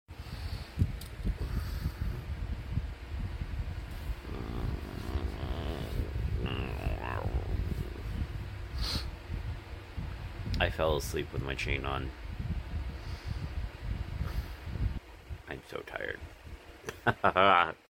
*grumble noises* sound effects free download